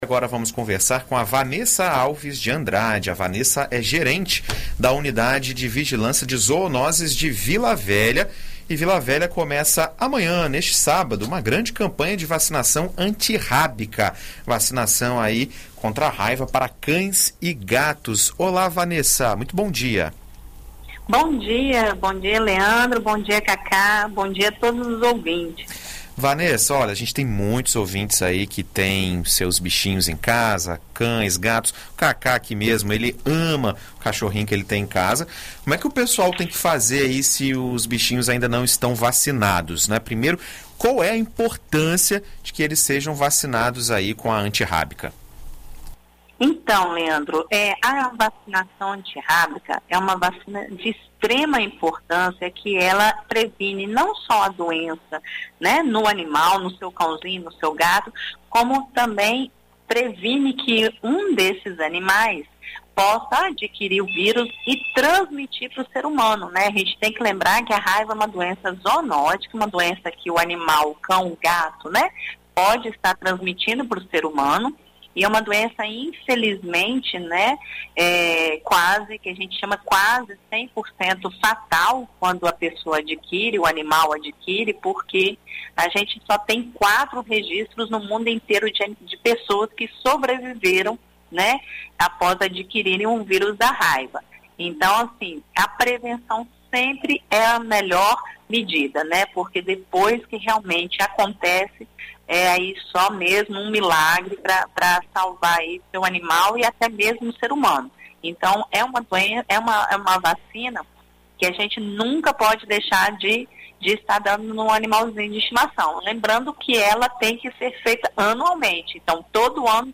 Em entrevista à BandNews FM Espírito Santo